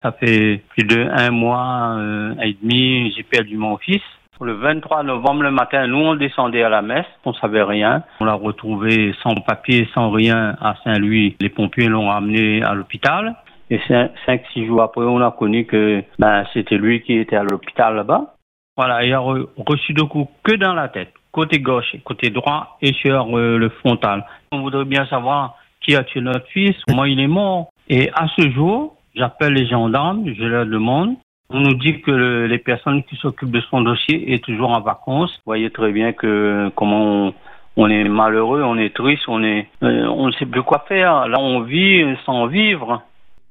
C’est une parole lourde, brisée, mais nécessaire.